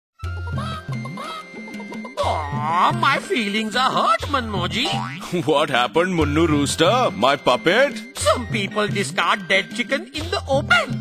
This is fourth in the series of five Radio PSA and address backyard farmers and their families. It also uses a performer and a rooster puppet as a creative medium to alert families to poultry diseases and instill safe poultry behaviours.
Radio PSA